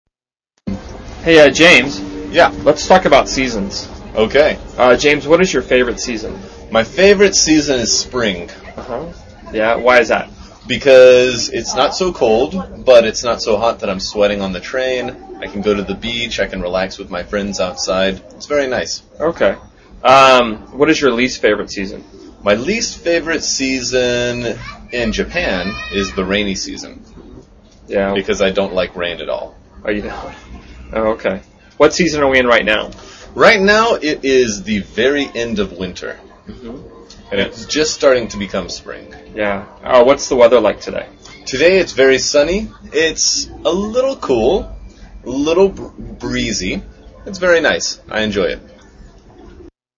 英语高级口语对话正常语速04：天气（MP3）